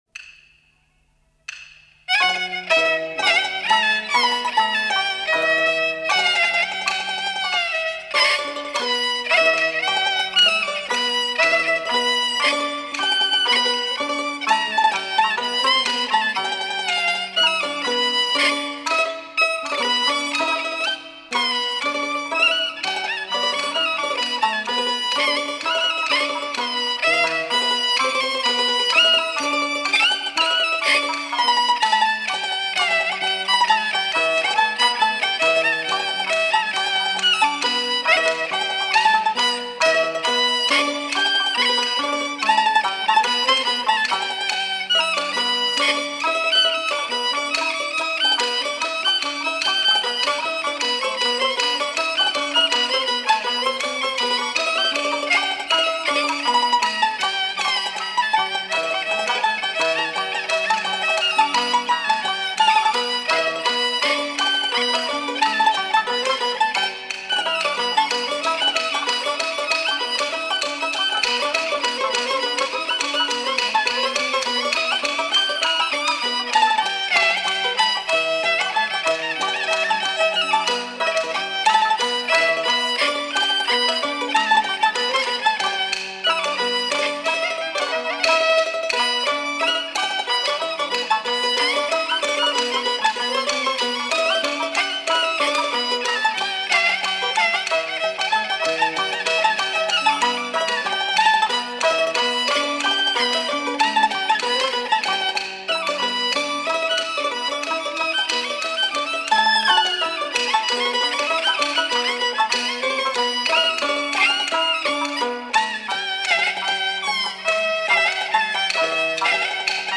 0037-京胡名曲小开门.mp3